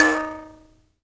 Index of /90_sSampleCDs/Roland LCDP11 Africa VOL-1/PLK_Buzz Kalimba/PLK_HiBz Kalimba